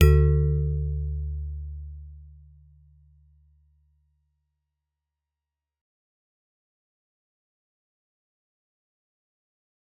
G_Musicbox-D2-f.wav